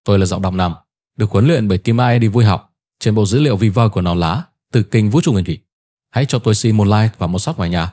vi_male_viphone.onnx